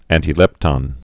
(ăntē-lĕptŏn, ăntī-)